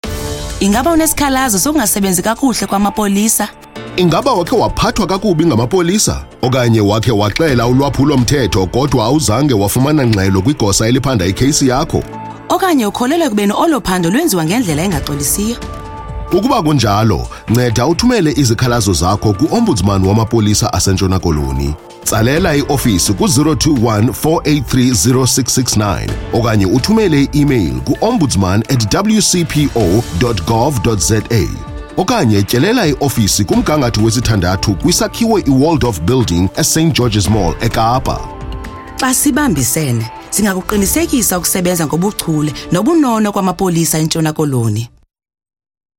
English Radio Ad: WCG-35-215-E.MP3 (MP3, 1.38 MB) Afrikaans Radio Ad: WCG-35-215-A.MP3 (MP3, 1.38 MB) isiXhosa Radio Ad: WCG-45-216-X.mp3 (mp3, 757.41 KB)